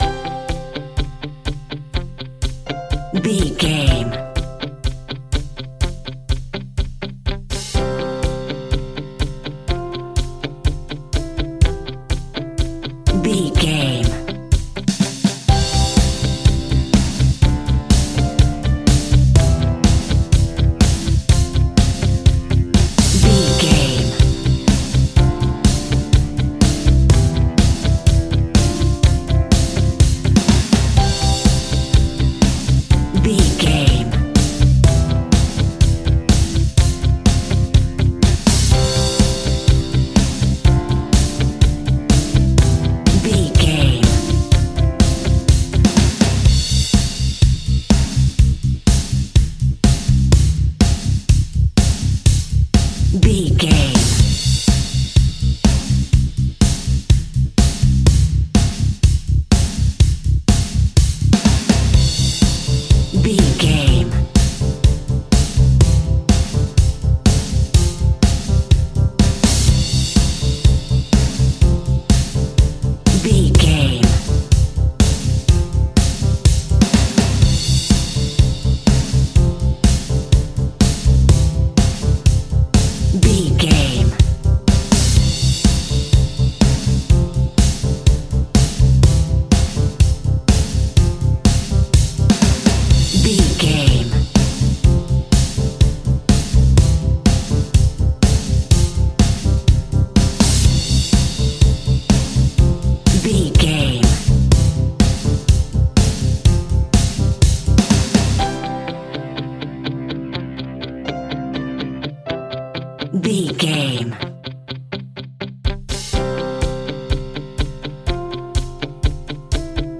Fast paced
Ionian/Major
indie pop
energetic
uplifting
instrumentals
guitars
bass
drums
piano
organ